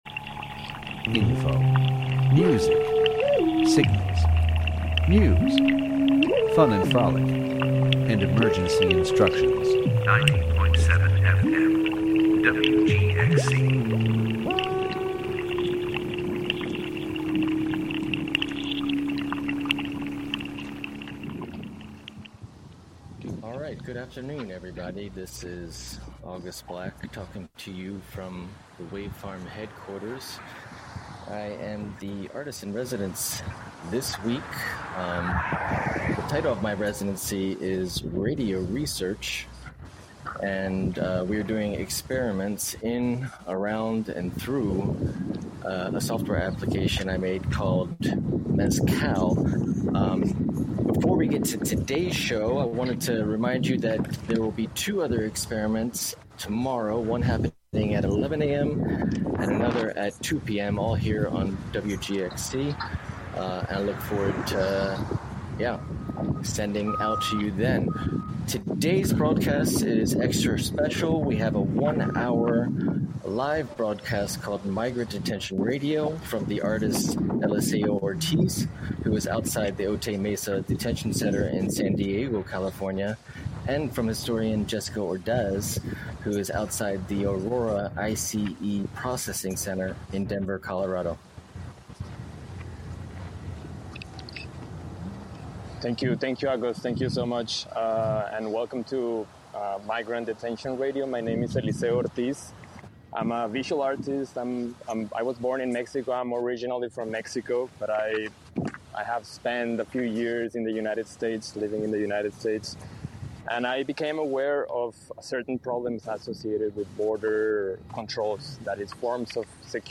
live one-hour radio conversation